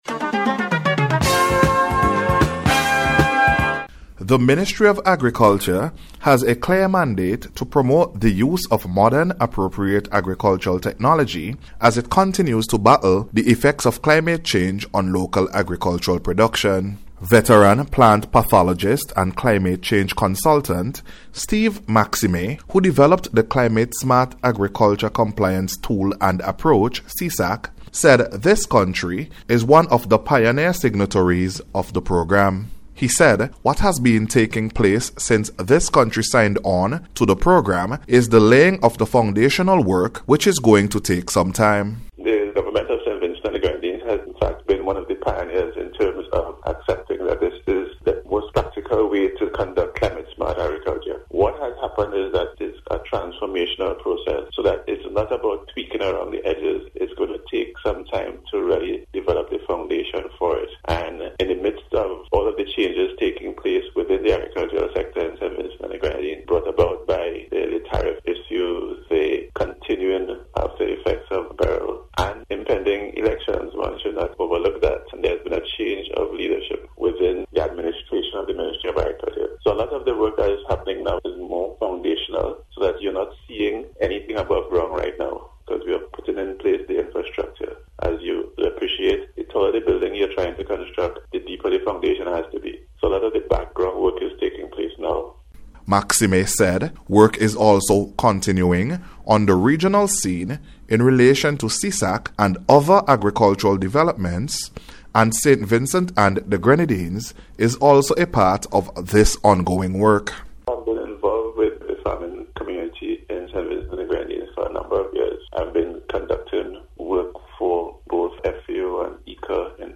NBC’s Special Report- Wednesday 23rd April,2025
SVG-CLIMATE-SMART-AGRICULTURE-WORK-REPORT.mp3